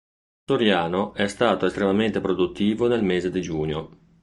Read more month Frequency A2 Hyphenated as mé‧se Pronounced as (IPA) /ˈme.ze/ Etymology From Latin mēnsem (“month”).